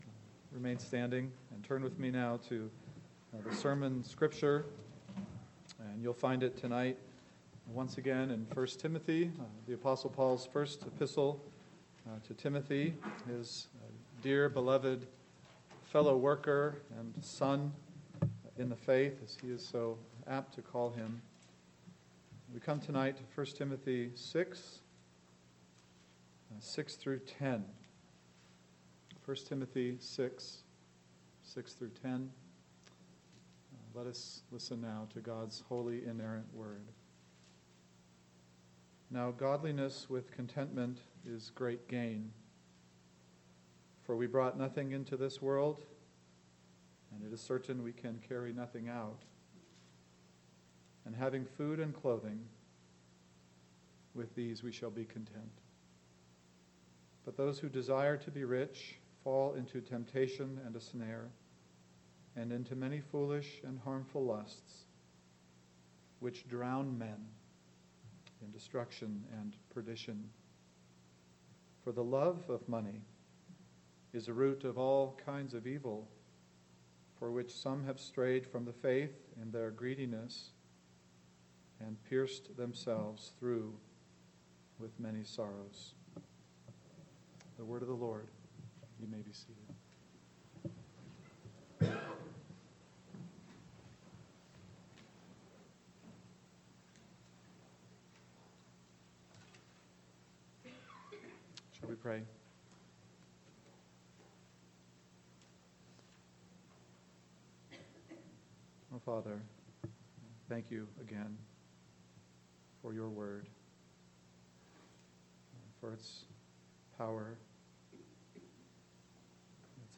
PM Sermon